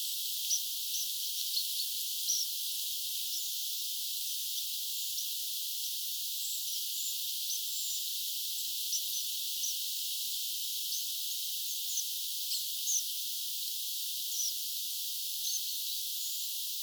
pyrstötiainen, metsäkirvinen, punakylkirastas ja peippo?
ilm_pyrstotiainen_metsakivinen_punakylkirastas_ja_peippo.mp3